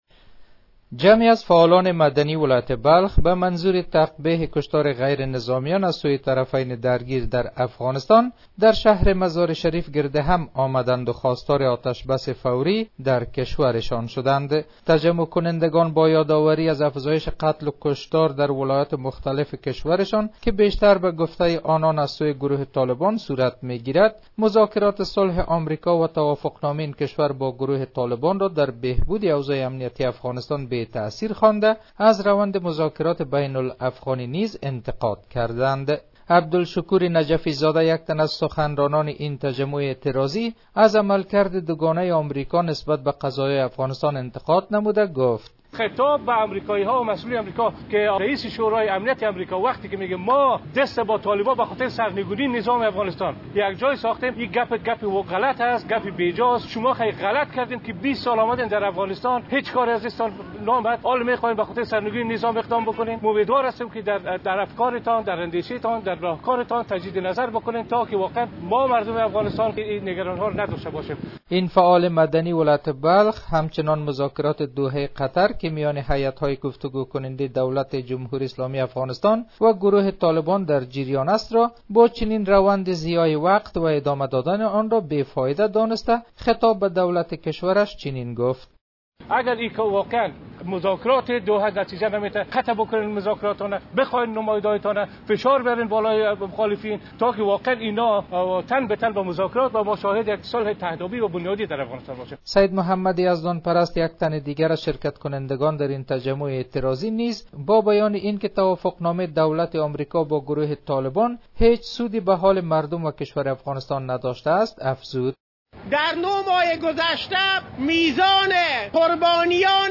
به گزارش خبرنگار رادیودری